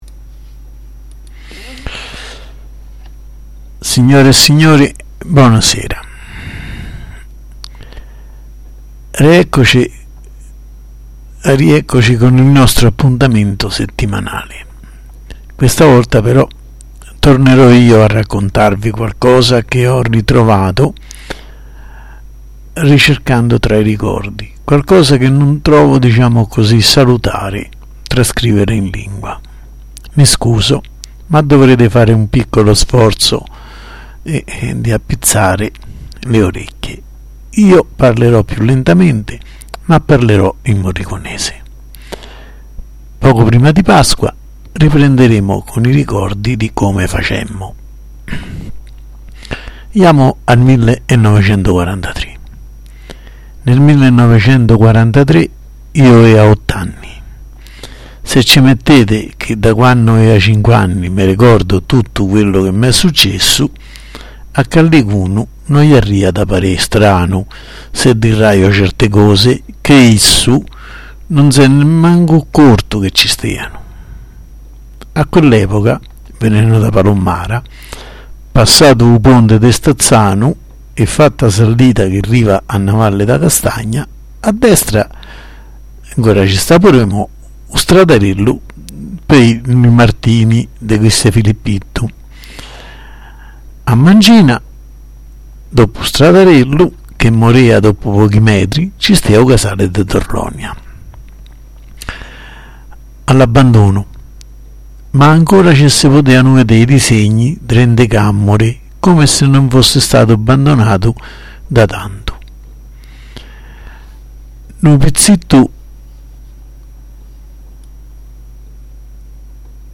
Mi scuso, ma dovrete fare un piccolo sforzo di appizzare le orecchie, io parlerò più lentamente ma parlerò in murricónese.